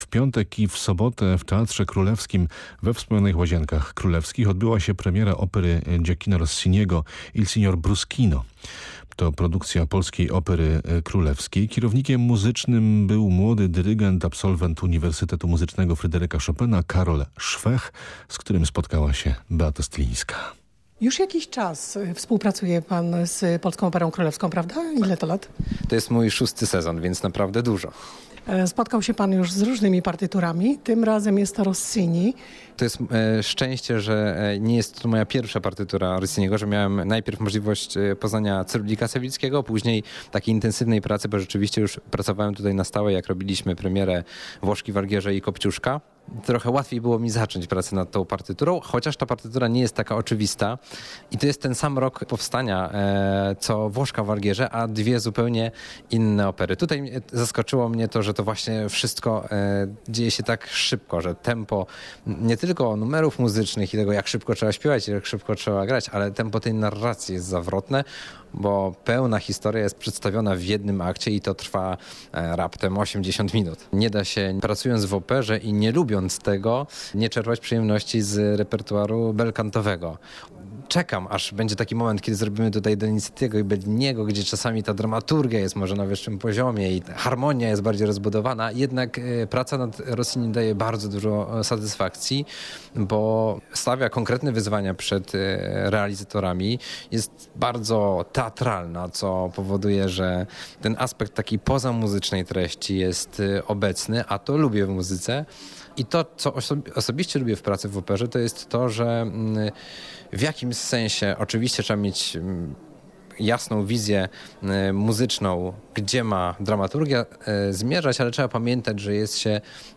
przy fortepianie. Tak kończy się licytacja na rzecz WOŚP - 23.02.2026